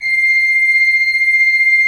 Index of /90_sSampleCDs/Propeller Island - Cathedral Organ/Partition G/HOLZGEDAKT M